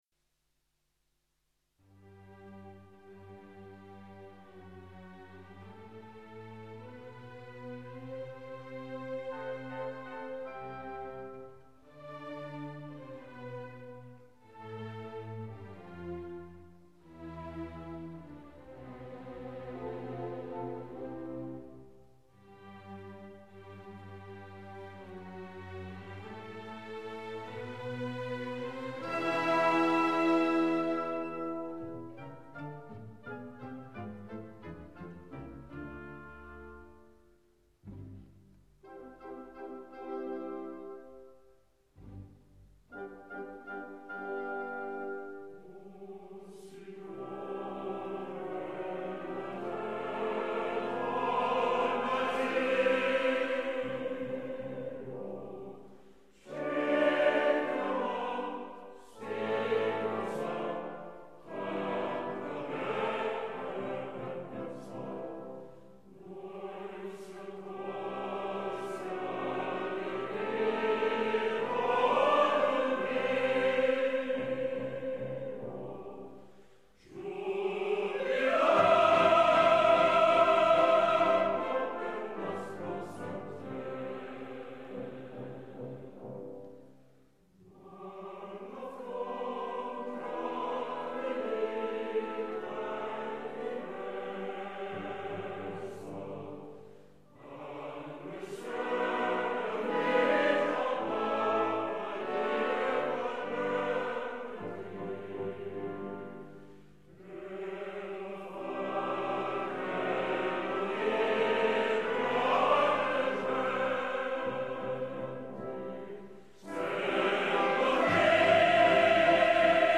Этот хор из "Ломбардцев" тоже очень, очень красив!